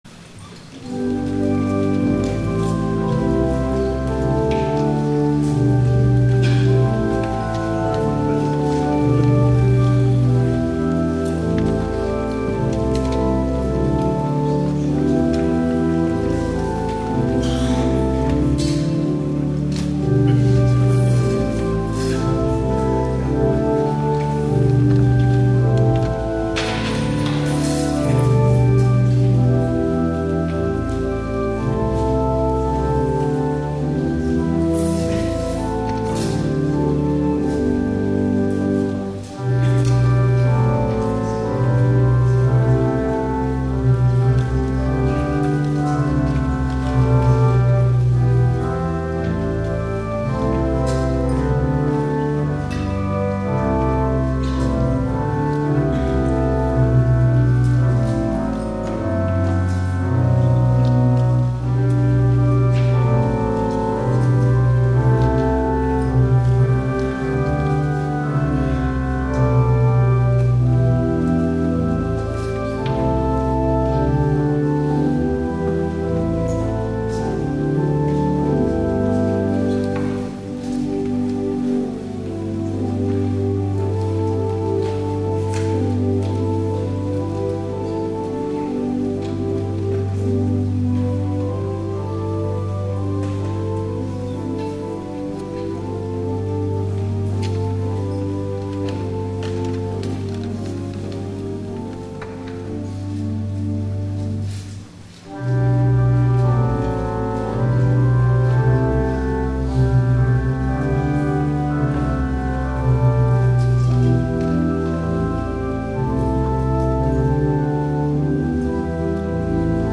DAY OF PENTECOST
THE OFFERTORY